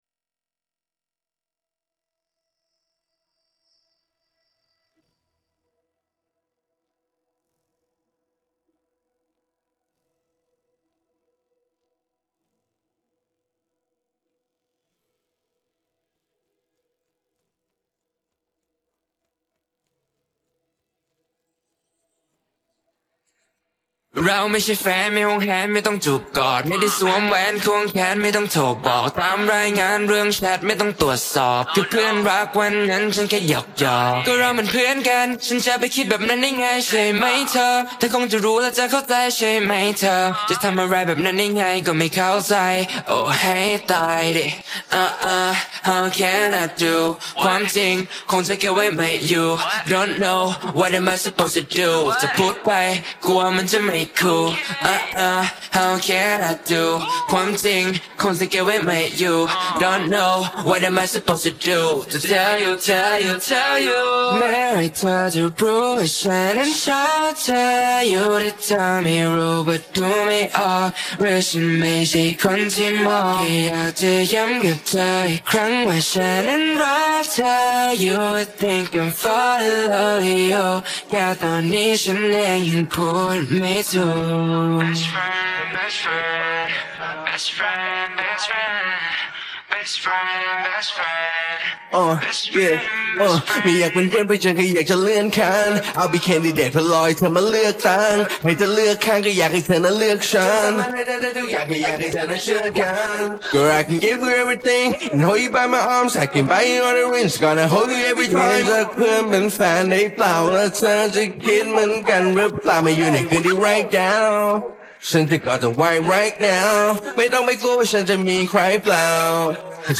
ส่วนเสียง